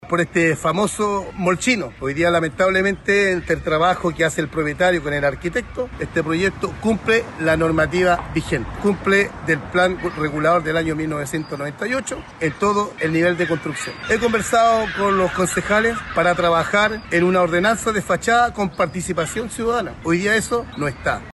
Sobre lo anterior se refirió el alcalde de Algarrobo, Marco Antonio González, precisando que la iniciativa cumple con la normativa vigente y con el plan regulador, aunque reconoció la necesidad de avanzar en regulaciones que resguarden el entorno urbano y patrimonial de la comuna.